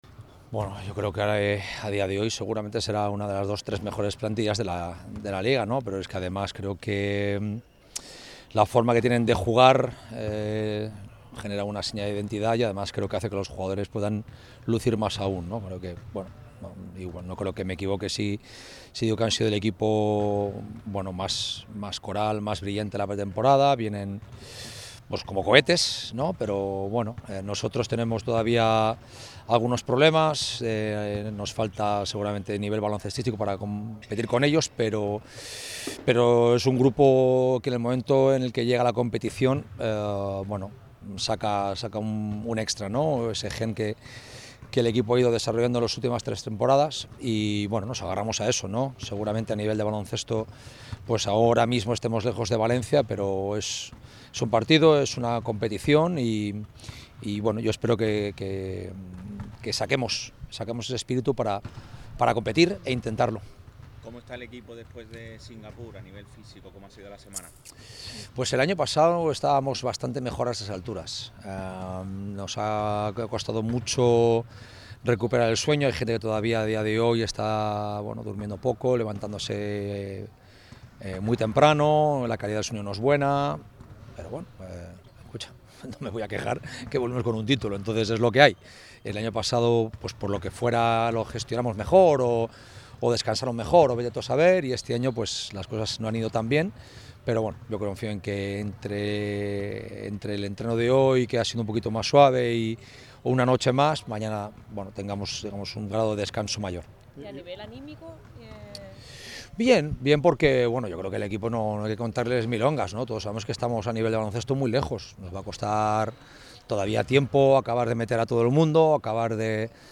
El técnico del Unicaja, Ibon Navarro, ha comparecido ante los medios en el Martín Carpena este viernes en la previa de la semifinal de la Supercopa Endesa ante Valencia Basket.